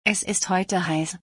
Pronunciation : ess ist hoy-teh hise